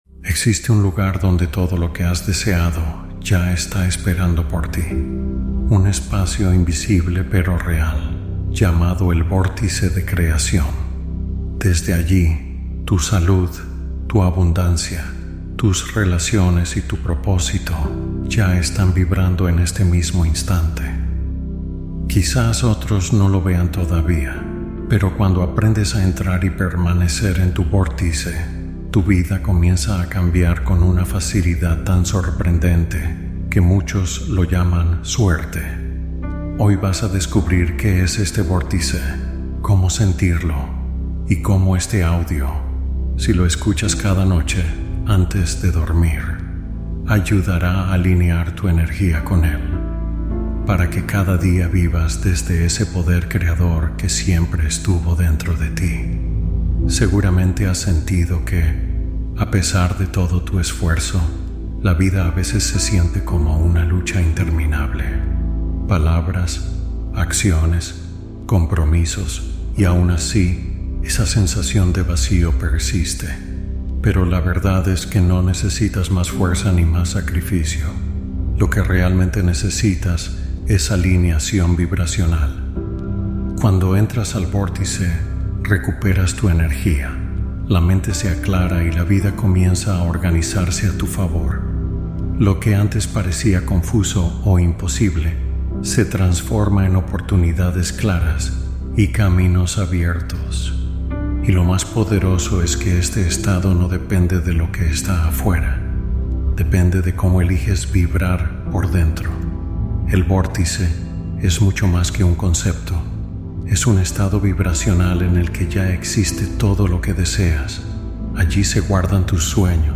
Meditación Para Entrar en Tu Etapa Más Afortunada